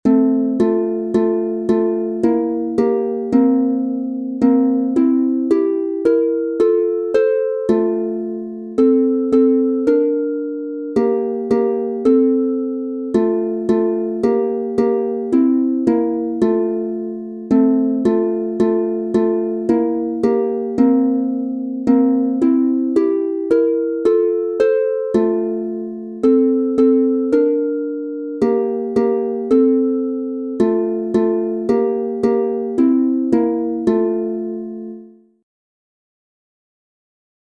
Hörprobe: (elektronisch eingespielt)
1. St. Solo + 2. St. + Noten, 1. Stimme Solo, 2. Stimme